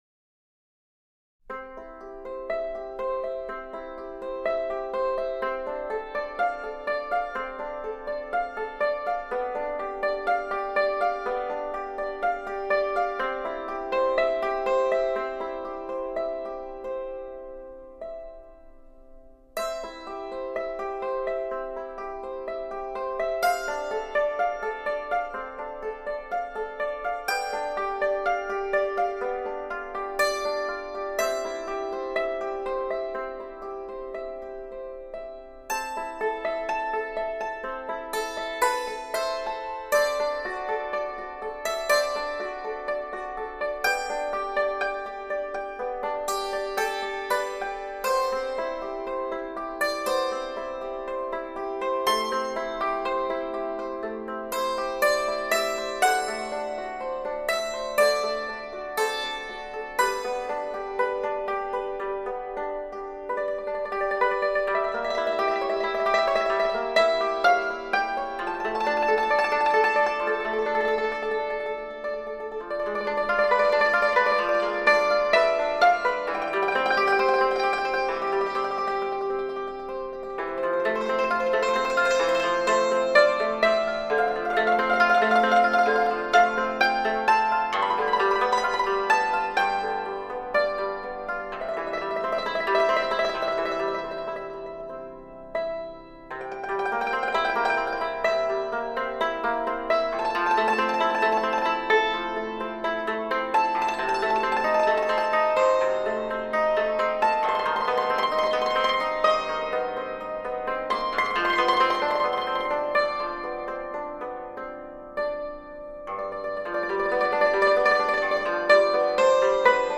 以清新的风格，超凡的技艺，演奏多首中外名曲，令大家耳目一新。